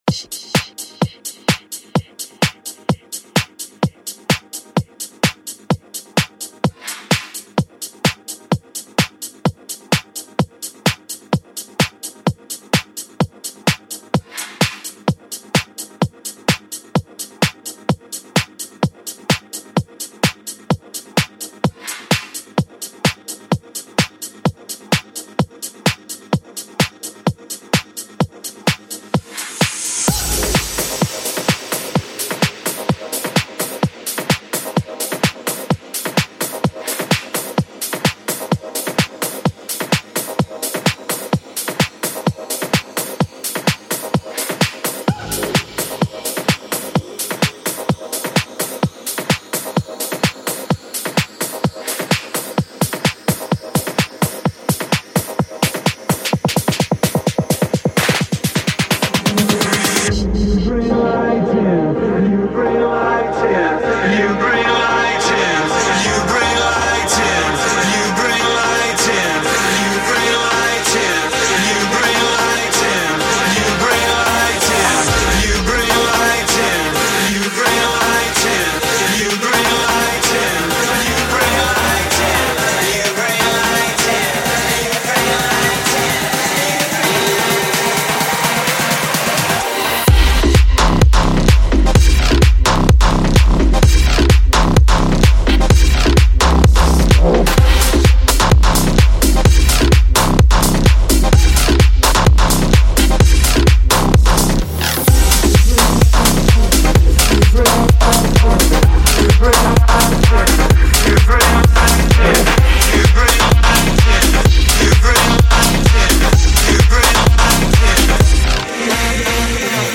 DJ Mixes and Radio Show